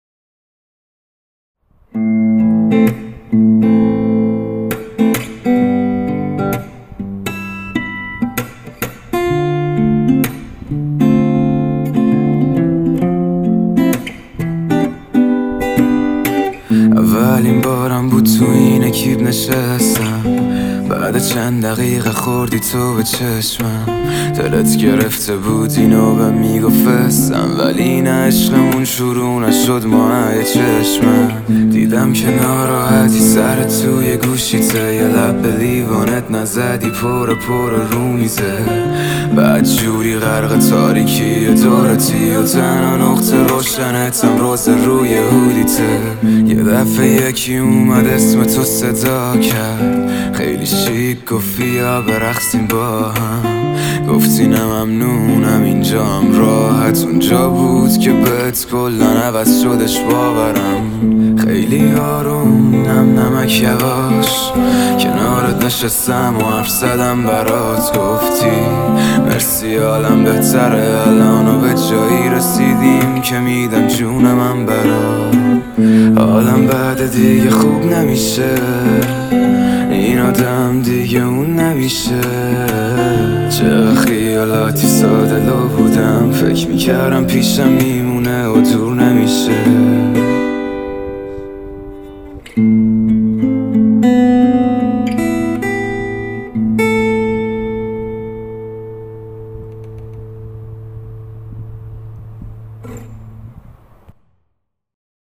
فری استایل